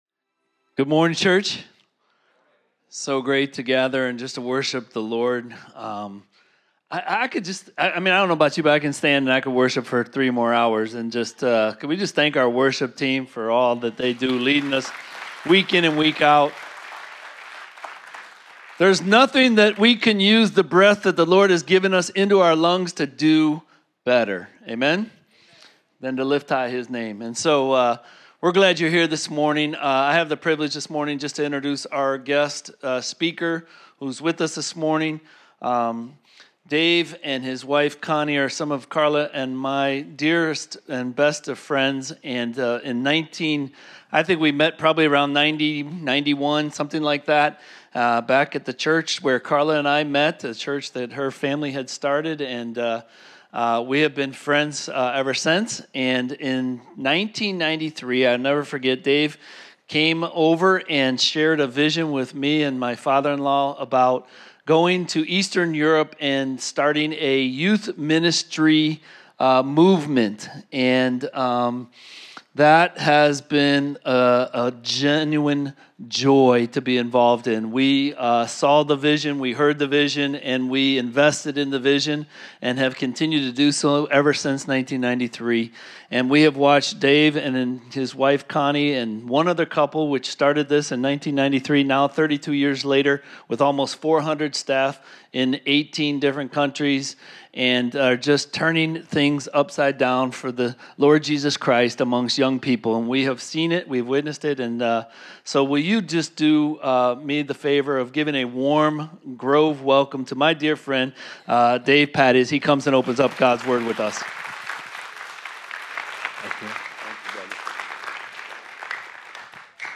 Home Sermons Guest Speaker